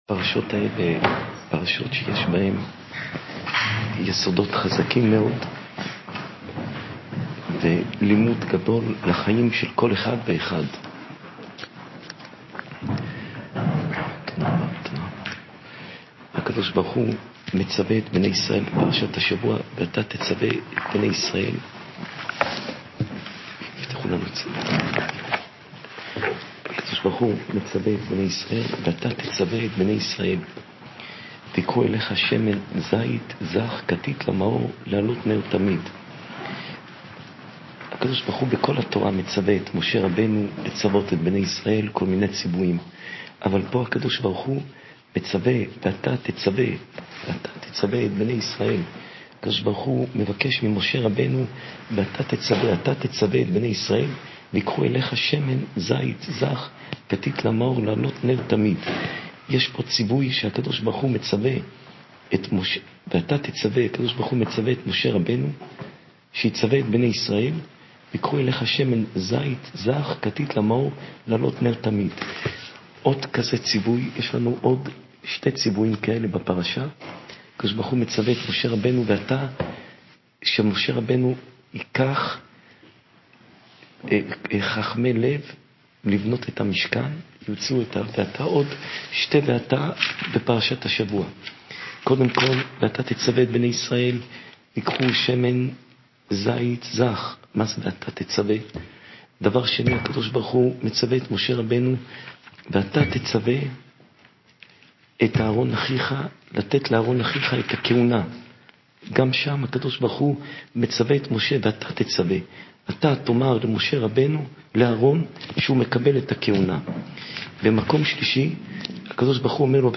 שעורי תורה מפי הרב יאשיהו יוסף פינטו